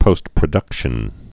(pōstprə-dŭkshən)